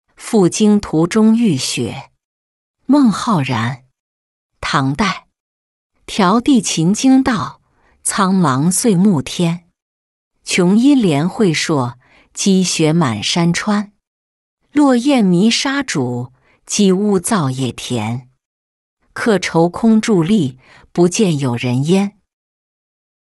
赴京途中遇雪-音频朗读